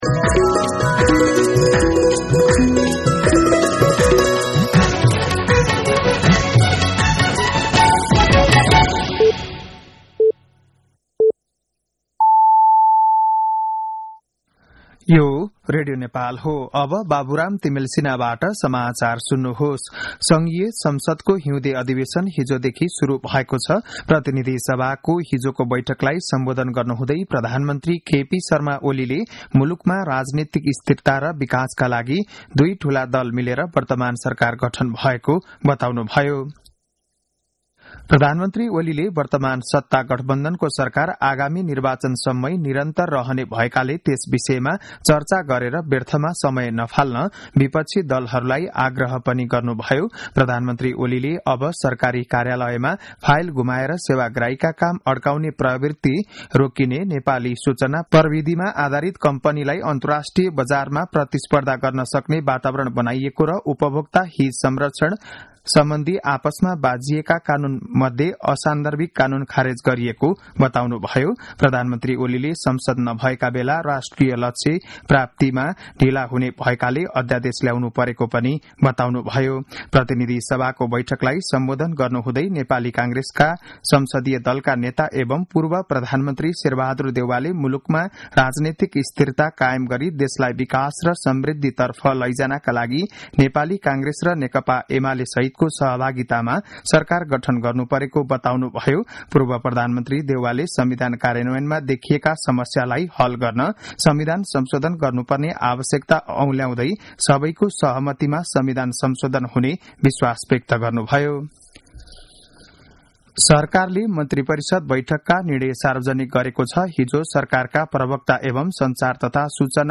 बिहान ११ बजेको नेपाली समाचार : २० माघ , २०८१
11-am-Nepali-News.mp3